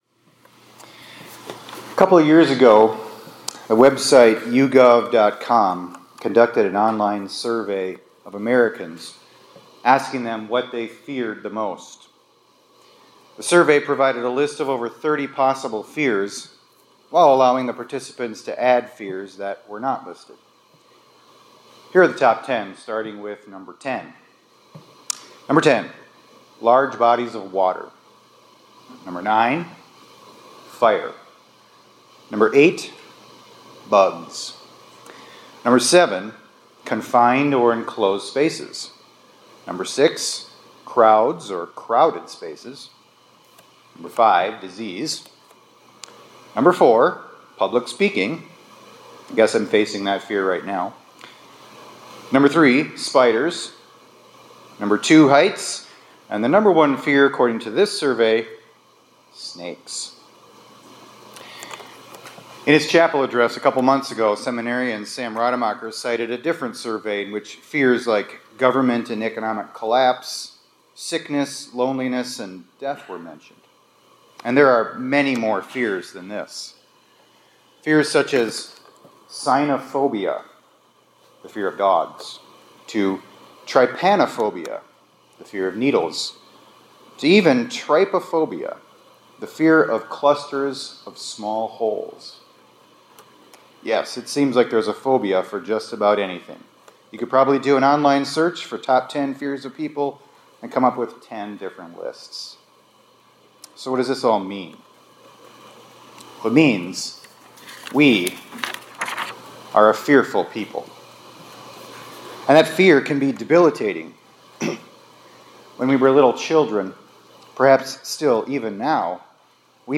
2025-01-24 ILC Chapel — Wherever You Go, Jesus is There